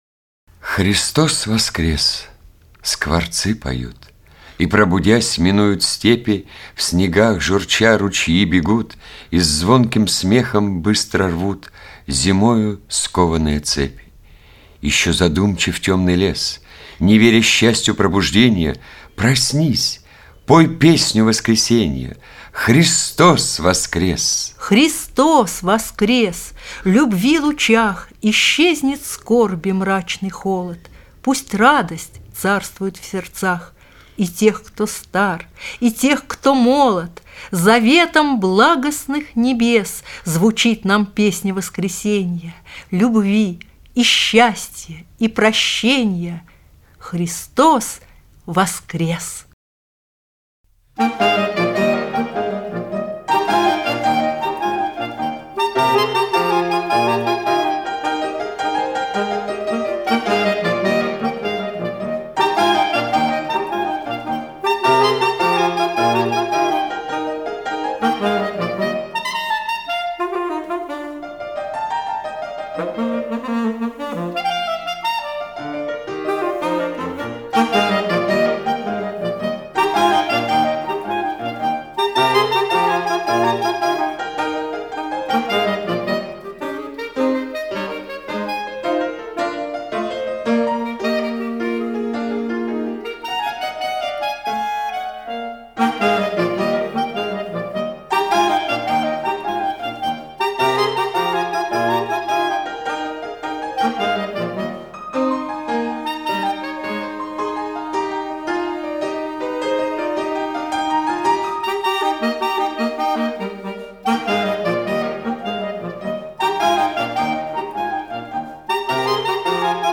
Стих о Христе - аудио стих - слушать онлайн